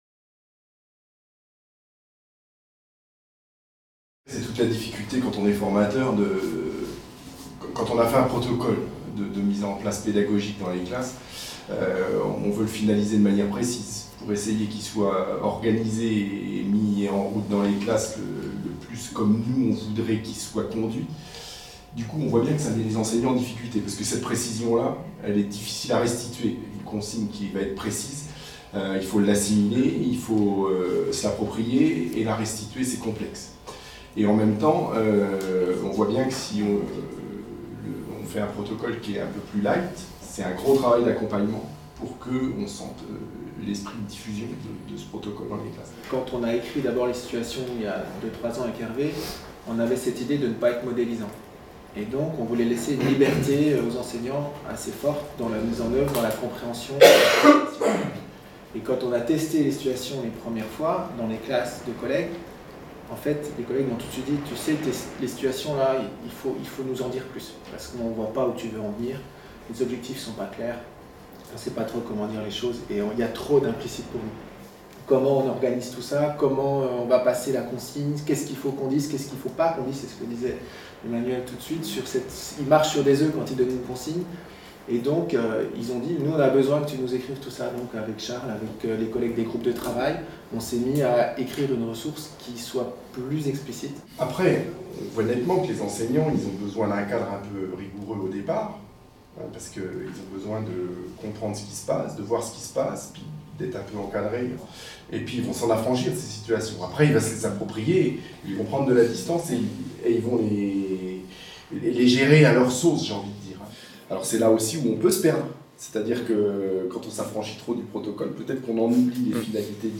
Les discussions entre formateurs mettent au jour des dilemmes entre leur conception de l'outil et la mise en oeuvre par les enseignants dans la classe.